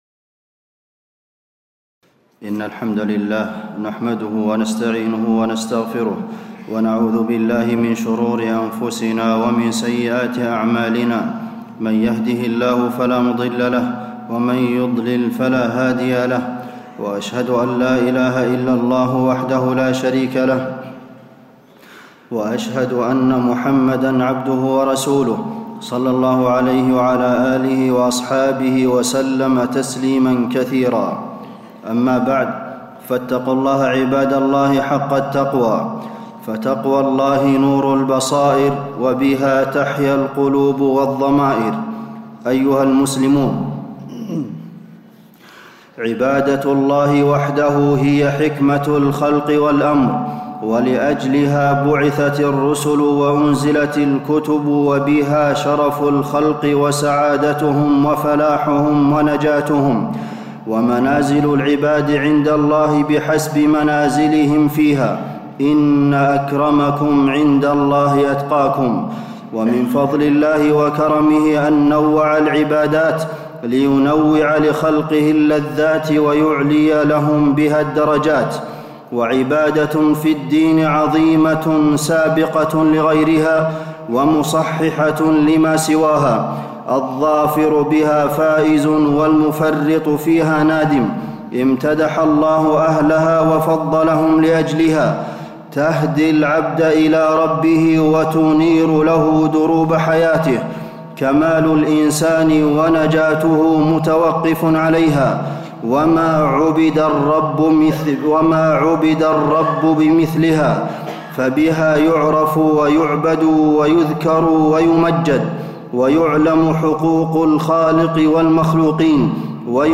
تاريخ النشر ١٩ ربيع الثاني ١٤٣٧ هـ المكان: المسجد النبوي الشيخ: فضيلة الشيخ د. عبدالمحسن بن محمد القاسم فضيلة الشيخ د. عبدالمحسن بن محمد القاسم العلم فضائله وثماره